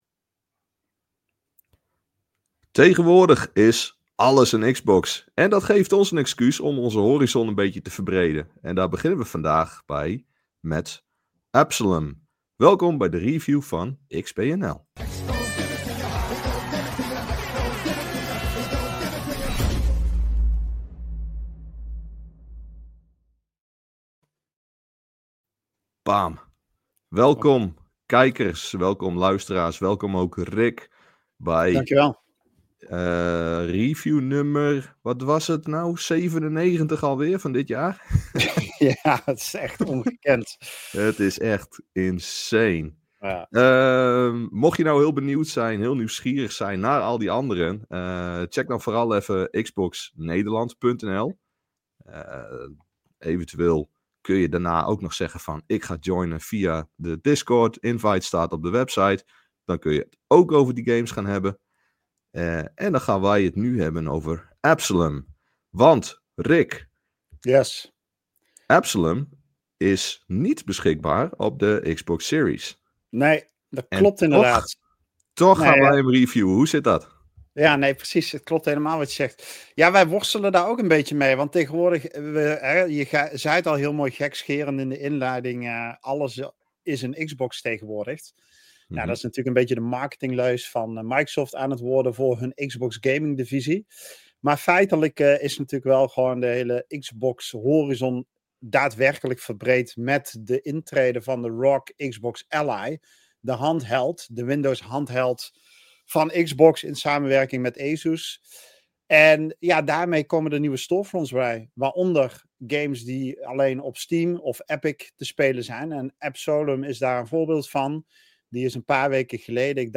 In ‘De week met XBNL’ lopen de redacteuren van XboxNederland door het Xbox gamenieuws van de week.